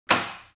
Door Snap Sound
household
Door Snap